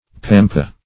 pampa \pam"pa\, n.